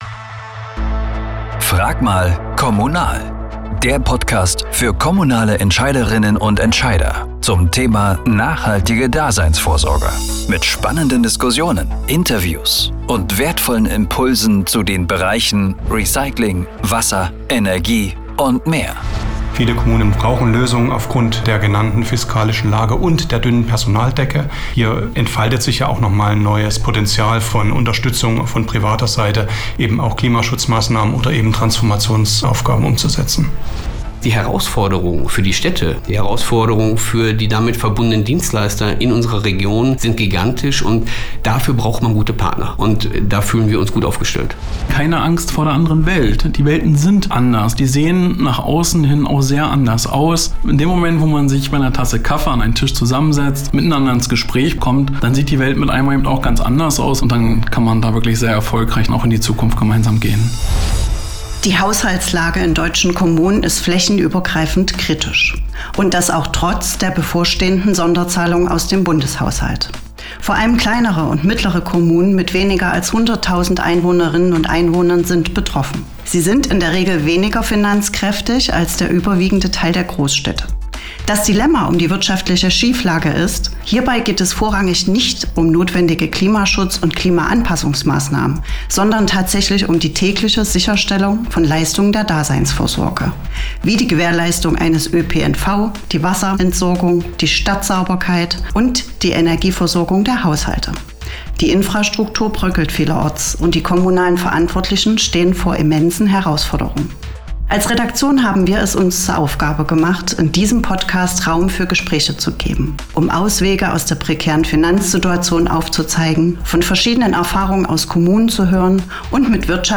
Eine Diskussion anlässlich des Deutschen Kämmerertags 2025 in Berlin